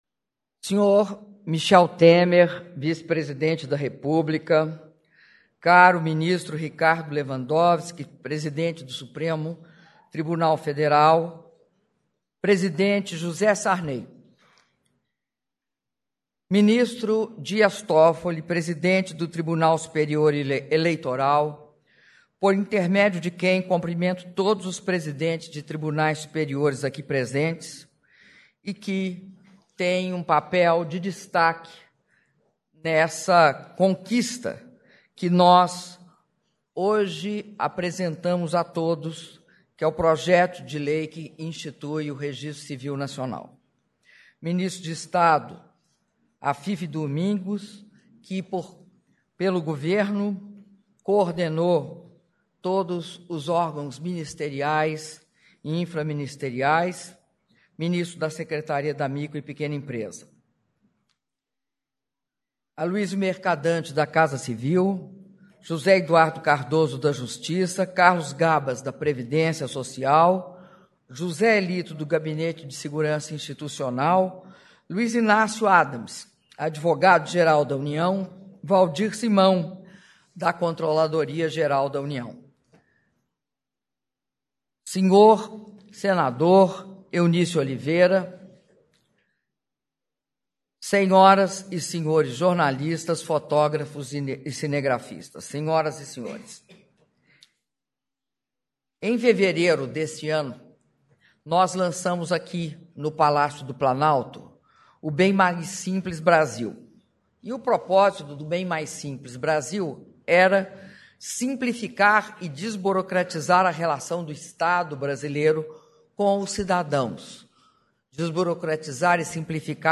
Áudio do discurso da presidenta da República, Dilma Rousseff, durante cerimônia de assinatura da mensagem que encaminha o Projeto de Lei que institui o Registro Civil Nacional - Brasília/DF (09min22s)